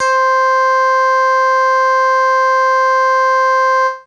55bc-bse19-c5.aif